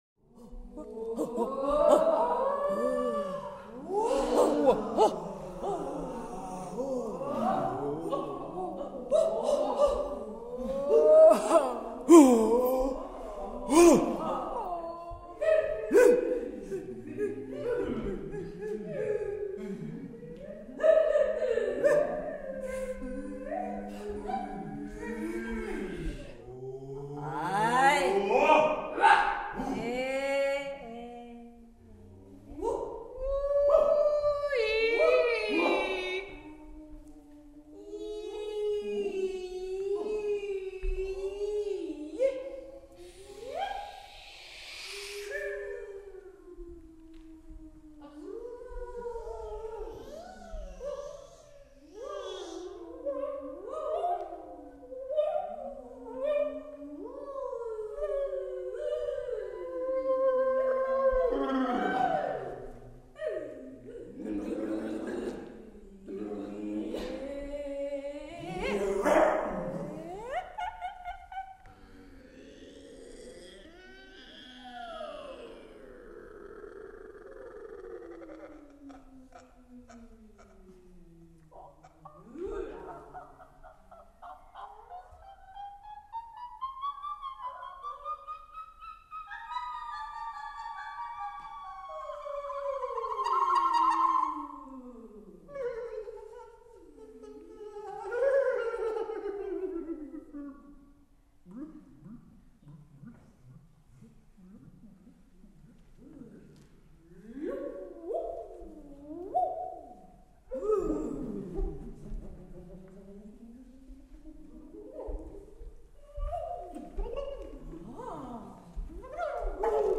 Quelques impros réalisées avec le groupe.